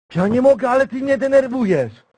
Worms speechbanks
youllregretthat.wav